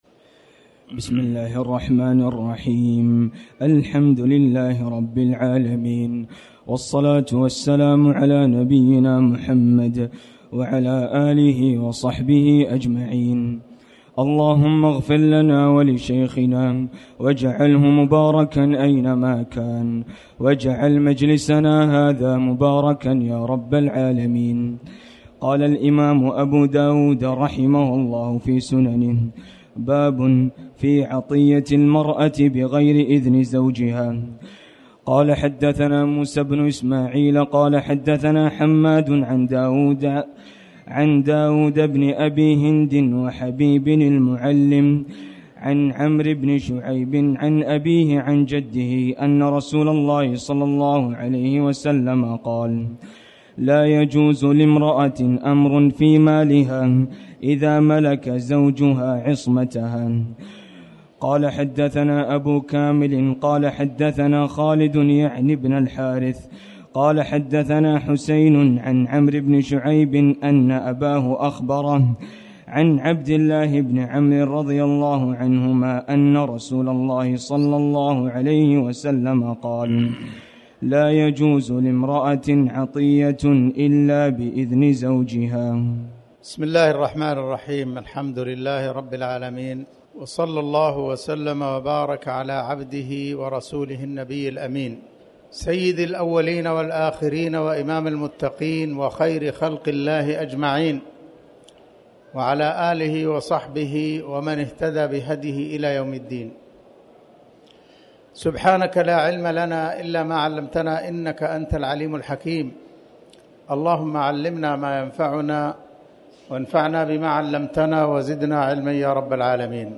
تاريخ النشر ١٠ ذو القعدة ١٤٤٠ هـ المكان: المسجد الحرام الشيخ